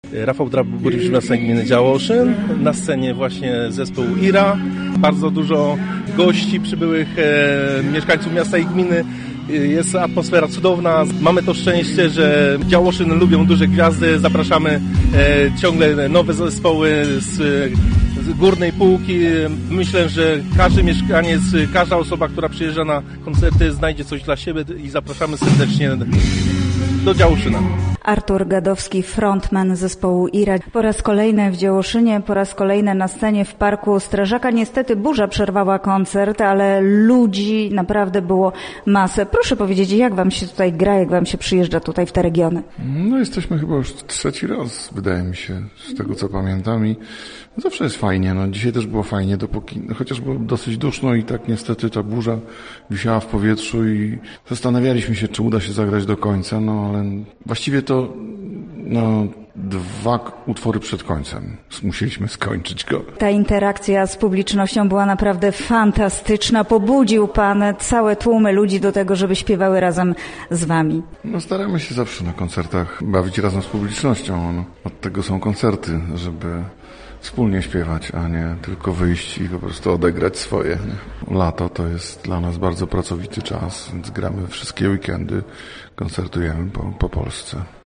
Jest bardzo dużo gości, a atmosfera cudowna – mówił nam podczas imprezy Rafał Drab, burmistrz Miasta i Gminy Działoszyn.
Dzisiaj też było fajnie dopóki burza nie przerwała koncertu, ale było bardzo duszno i ta burza wręcz wisiała w powietrzu – mówi Artur Gadowski, wokalista zespołu IRA.